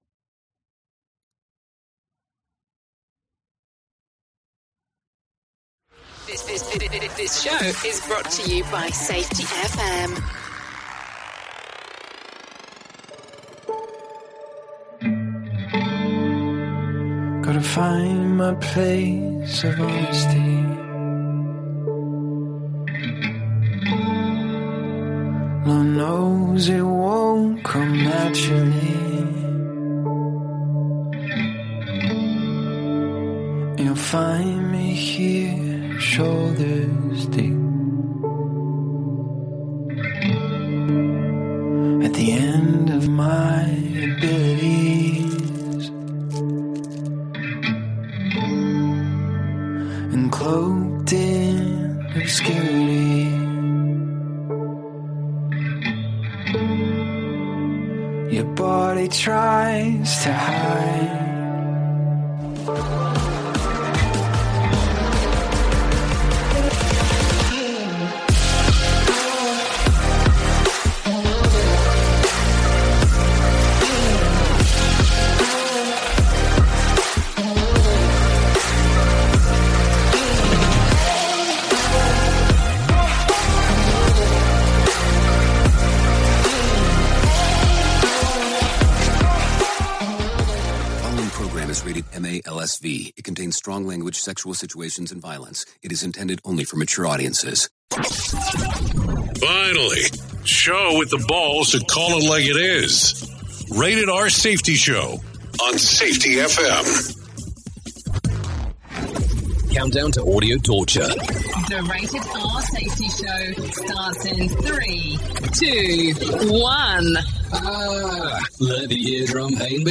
Expect nothing less than the most insightful and thought-provoking discussions on current events and crucial topics.